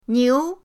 niu2.mp3